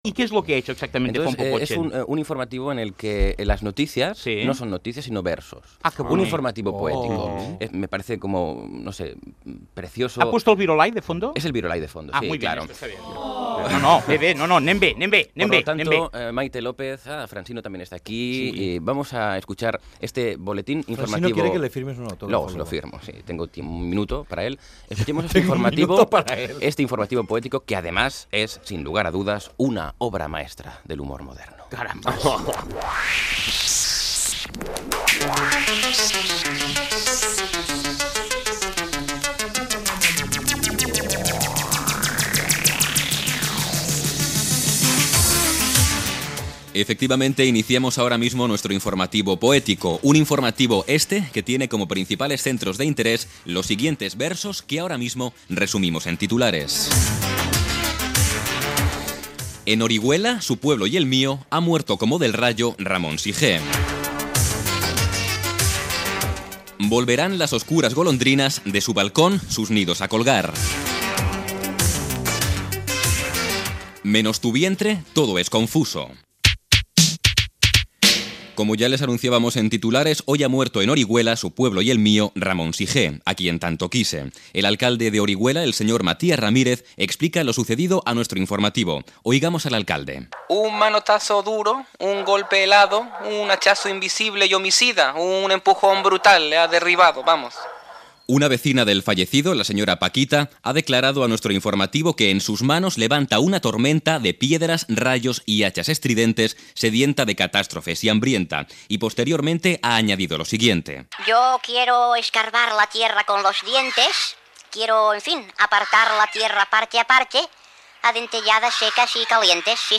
Secció d'humor amb Juan Carlos Ortega dedicada a fer un informatiu poètic
Entreteniment